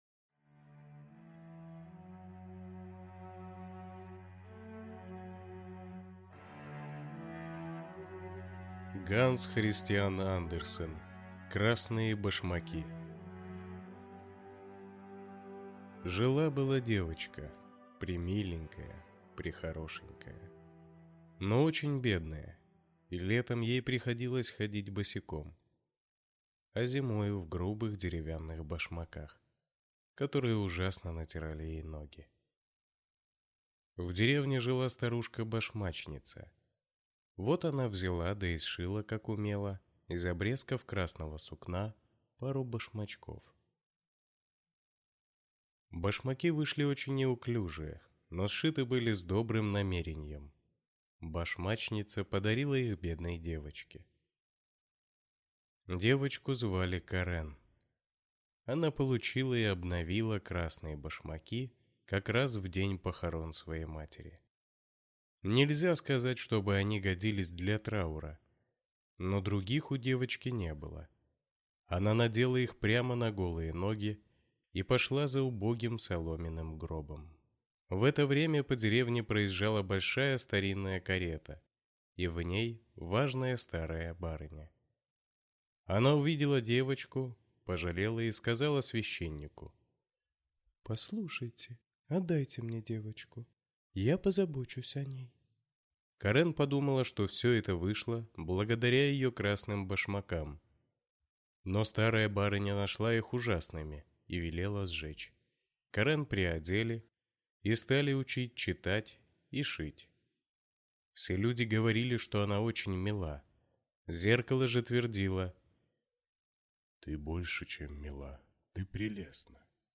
Аудиокнига Красные башмаки | Библиотека аудиокниг